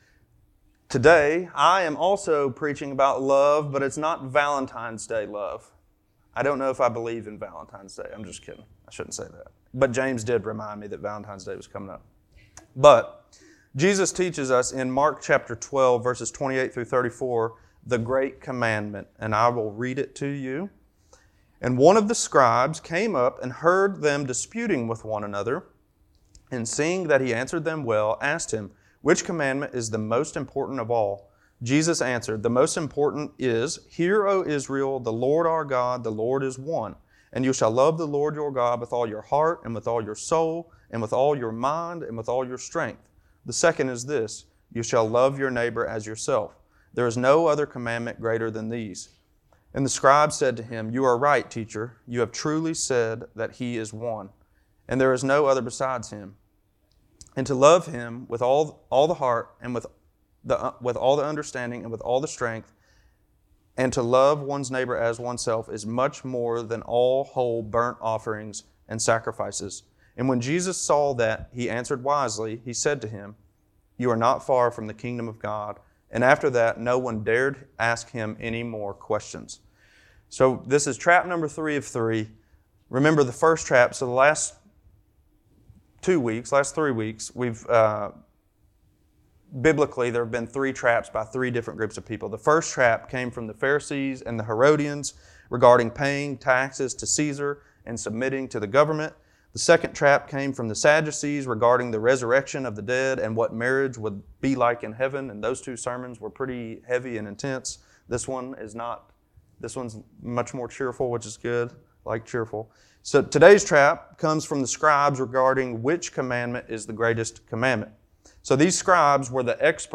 Sunday Morning Services | Belleview Baptist Church